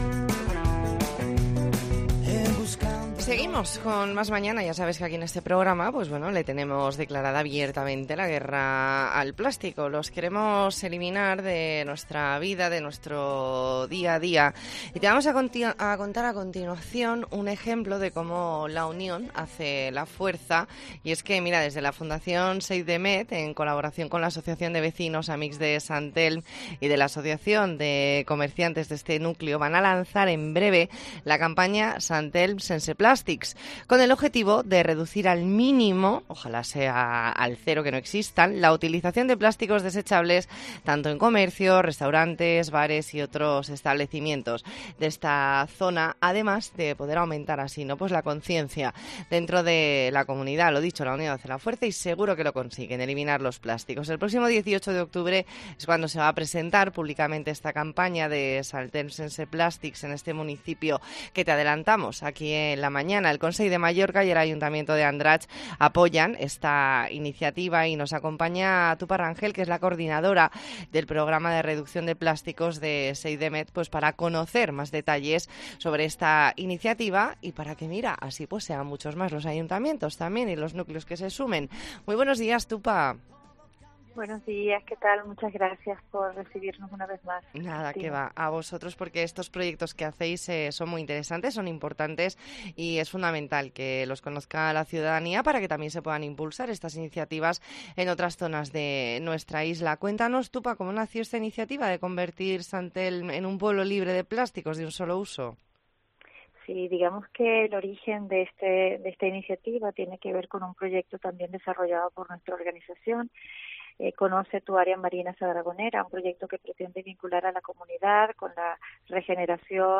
ntrevista en La Mañana en COPE Más Mallorca, martes 20 de septiembre de 2022.